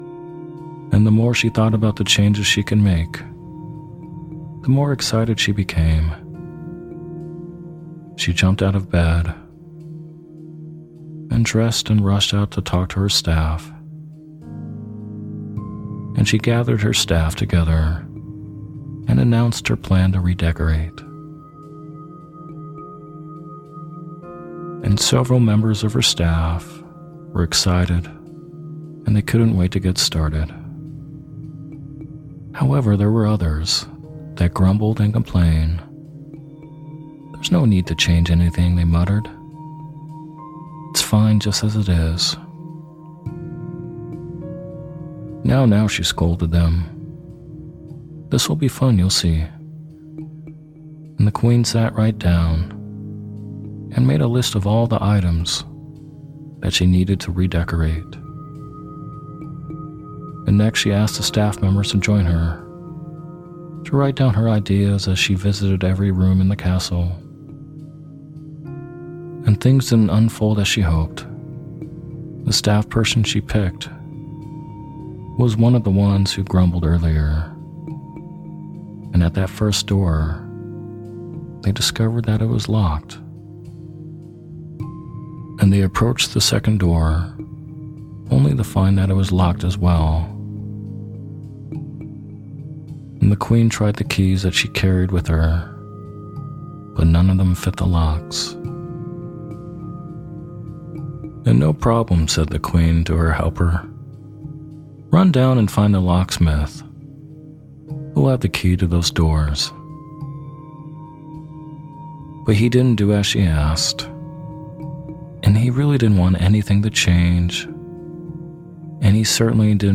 Story Based Meditation “Redecorating The Castle”